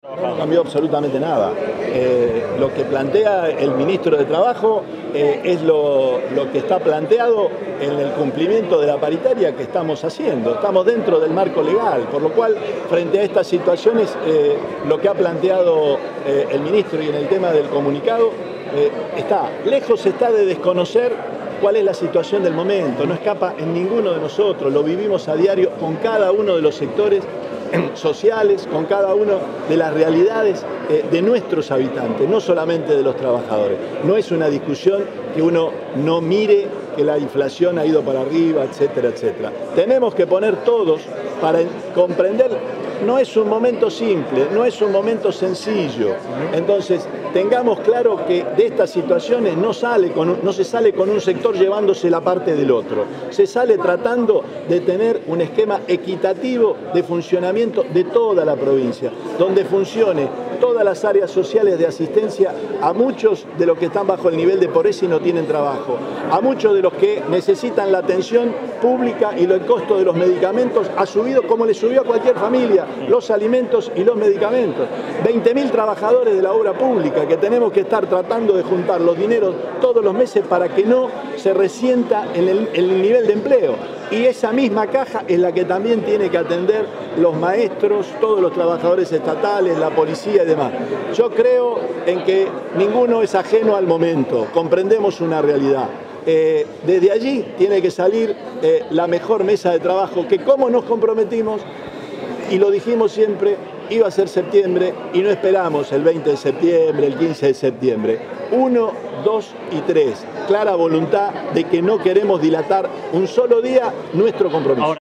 Declaraciones del Gobernador Omar Perotti